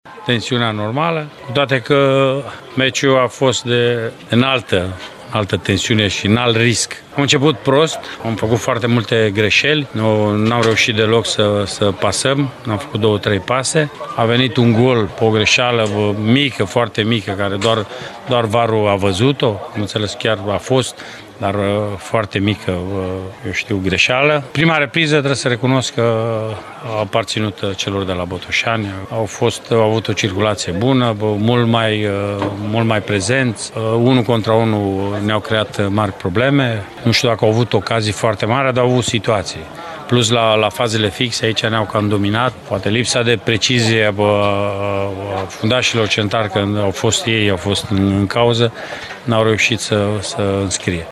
Antrenorul UTA-ei, Mircea Rednic, regretă faptul că echipa sa a contat prea puțin în prima repriză: